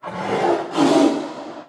sounds_tiger_snarl_04.ogg